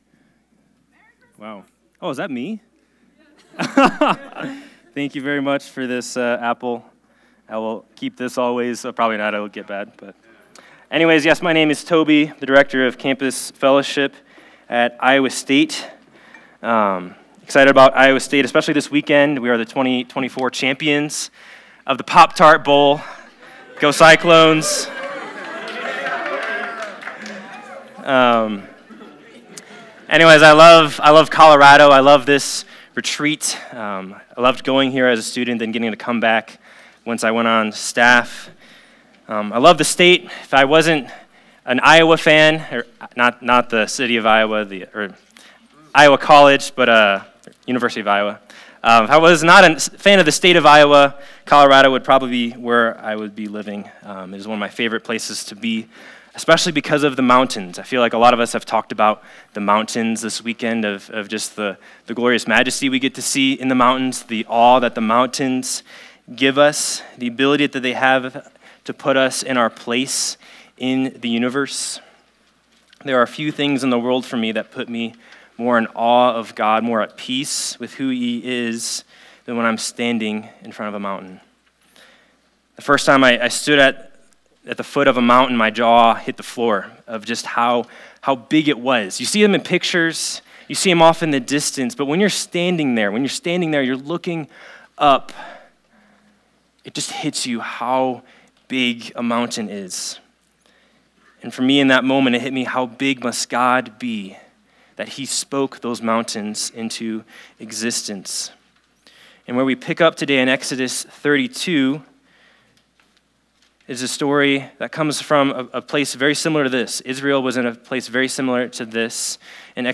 The Gold Calf | Winter Retreat 2024 | Hope Along the Way - Campus Fellowship